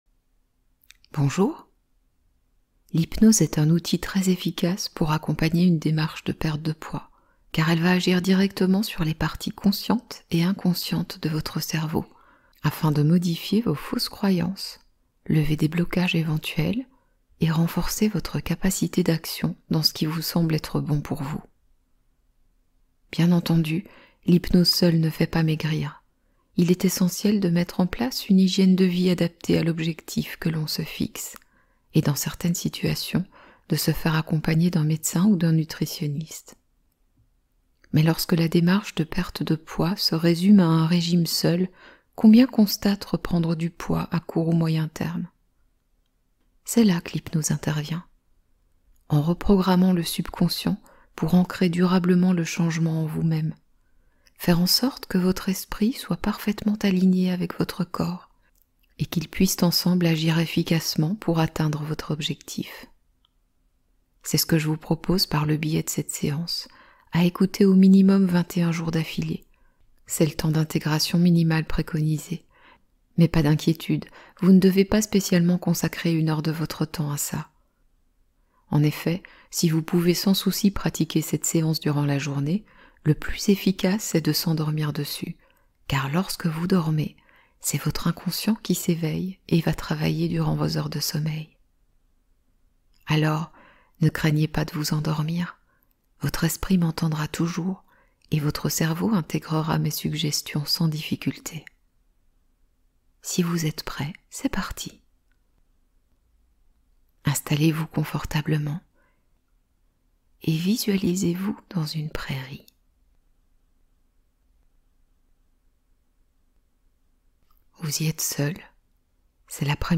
Hypnose de sommeil : soin énergétique pour apaiser l’esprit